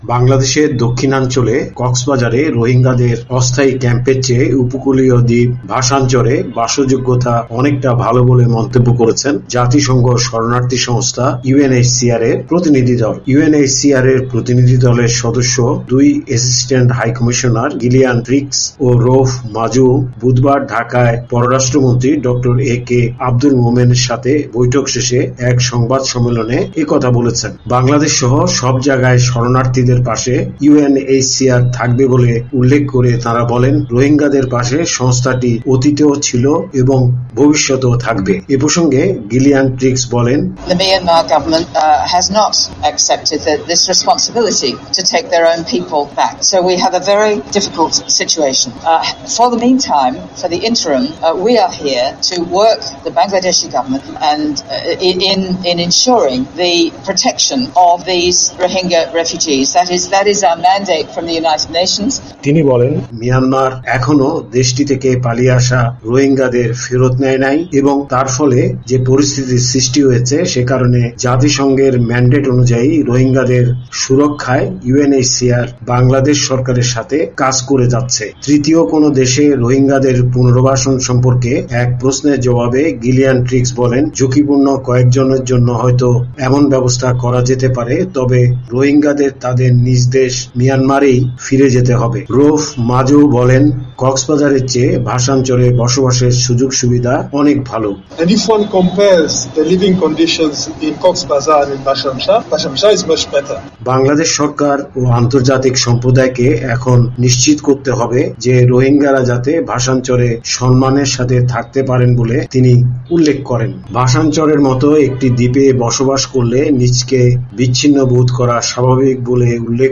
ইউএনএইচসিআর এর প্রতিনিধি দলের সদস্য দুই অ্যাসিস্ট্যান্ট হাই কমিশনার গিলিয়ান ট্রিগস ও রাউফ মাজুও বুধবার ঢাকায় পররাষ্ট্রমন্ত্রী ড. এ কে আব্দুল মোমেনের সাথে বৈঠক শেষে এক সংবাদ সম্মেলনে একথা বলেছেন।